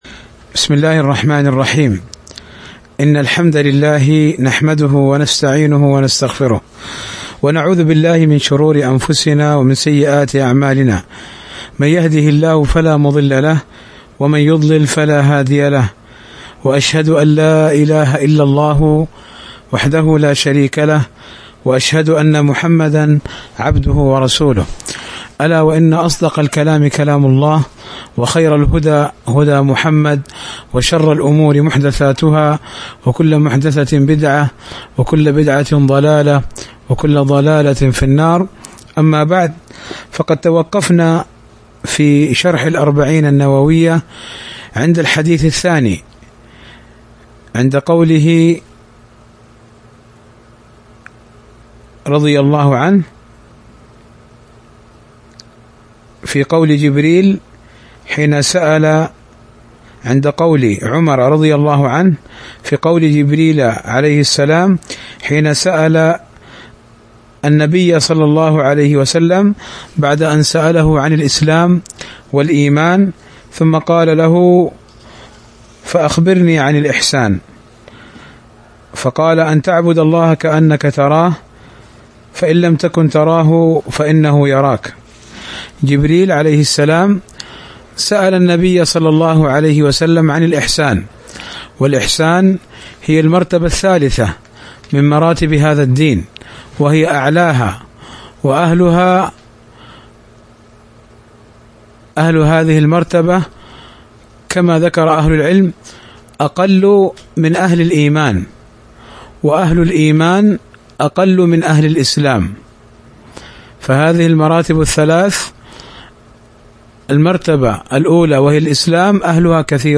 شرح الأربعون النووية الدرس 5